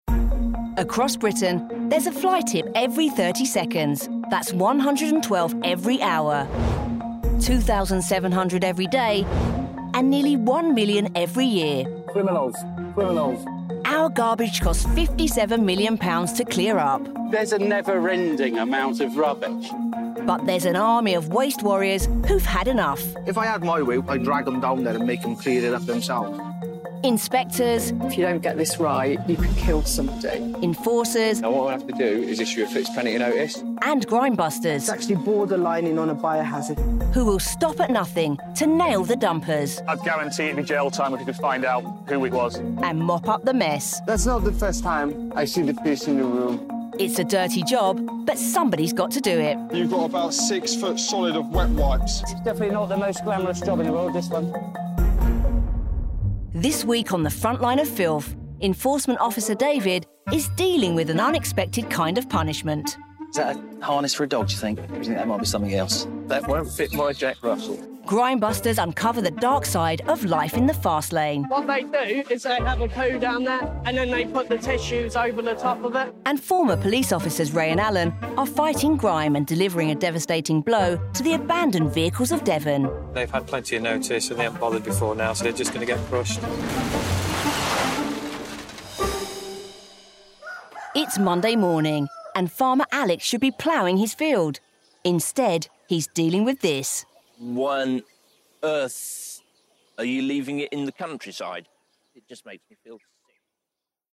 Commercial Showreel Deliveroo Special K Homebase Pantene Fairy P&O Dogs Trust Addicted to Food Grime and Punishment